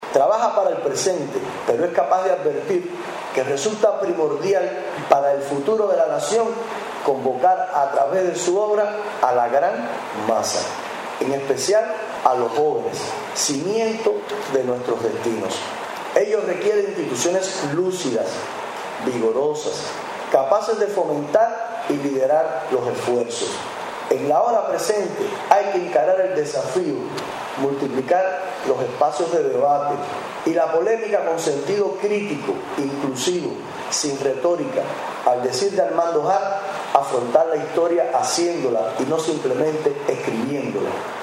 Titulada Cuba frente a la cruzada del neoliberalismo, la conferencia abordó el papel que desempeña nuestra isla en ese enfrentamiento al capitalismo mundial en su fase más reaccionaria, a través de un artículo del libro Cuba: ¿fin de la historia?